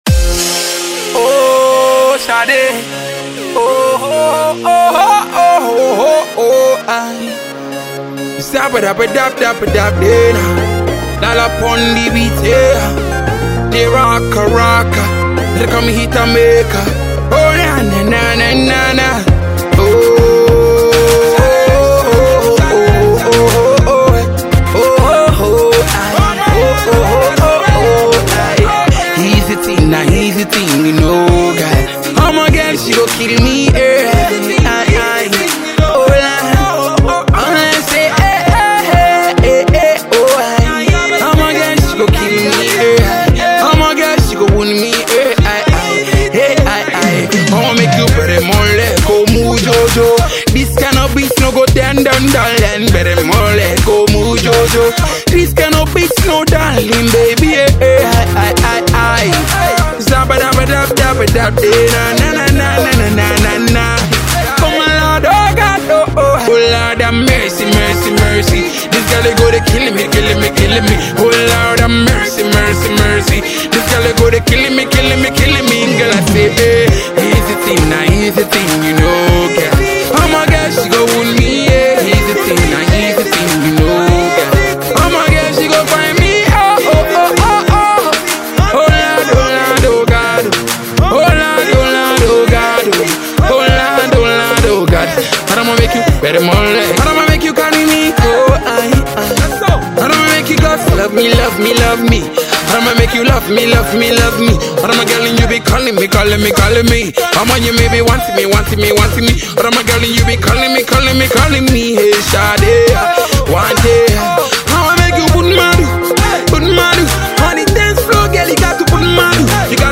gets on a patois flow